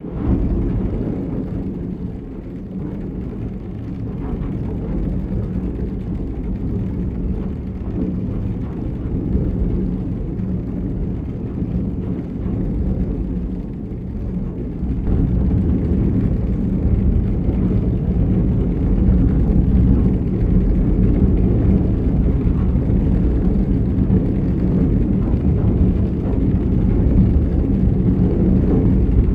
Door, Metallic, Slide, Long and Smooth, Bearings